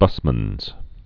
(bŭsmənz)